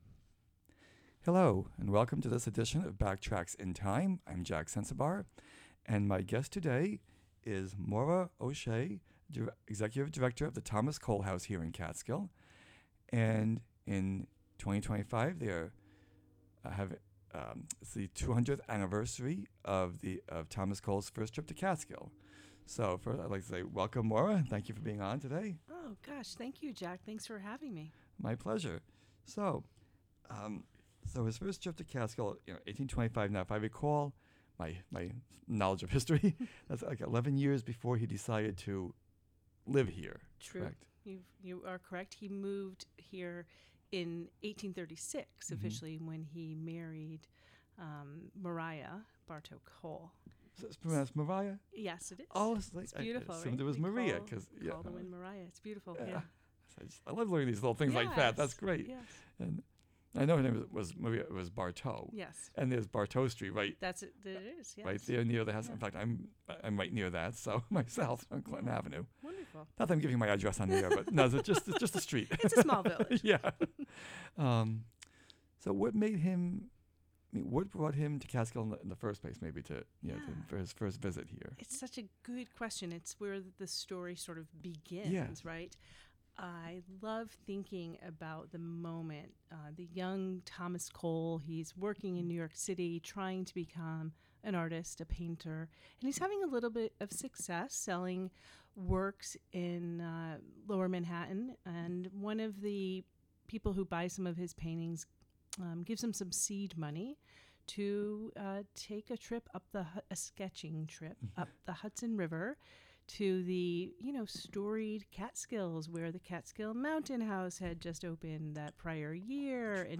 Dedicated to the history of Greene County, its notable people and places and the Hudson River. The show features interviews with local historians, longtime residents with stories to tell, and archival recorded material.